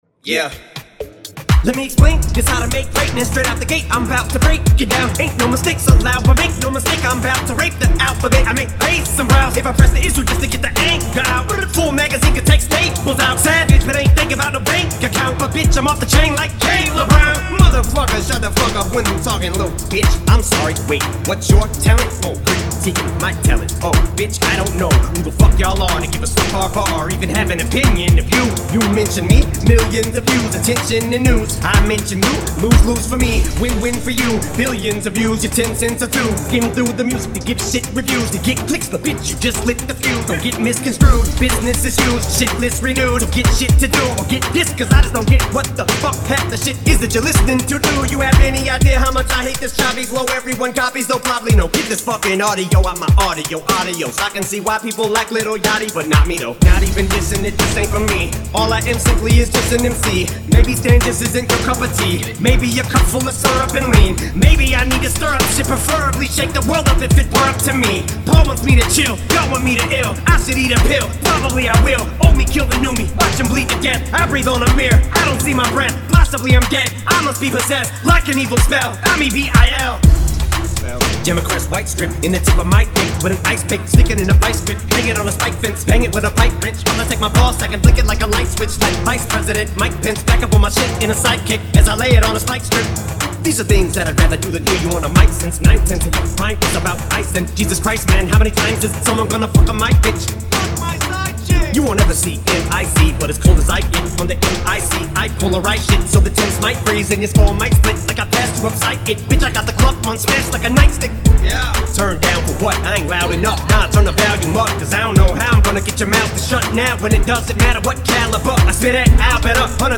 In the zip:- Intro Mix- Extended Vocal Mix